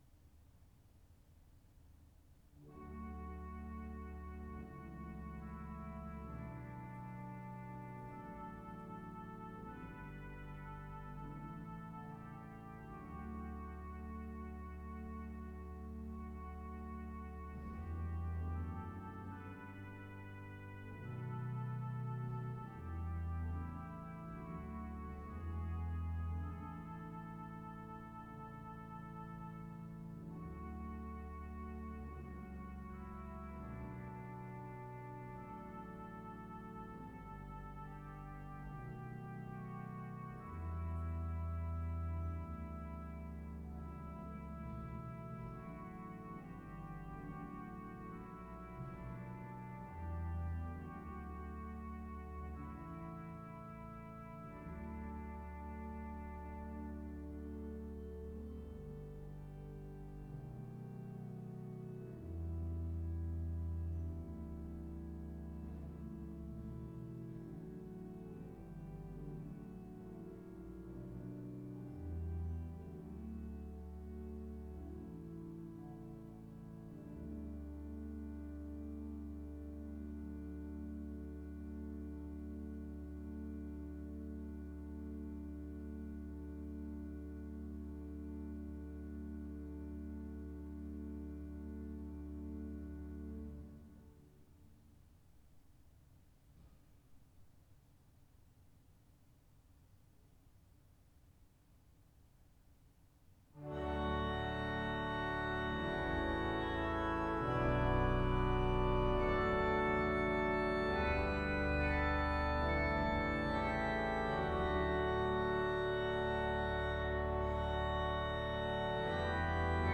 Bodø domkirke
O bli hos meg En av mine favoritter, ble litt lang da jeg håndregistrerte underveis.